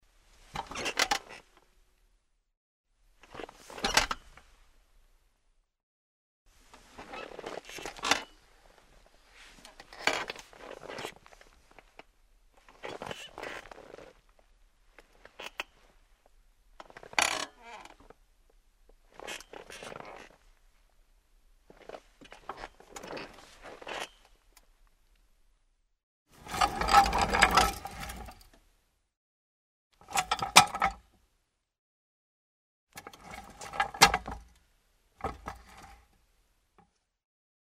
Звуки мебели
Офисный стул для сидения, вставания и движения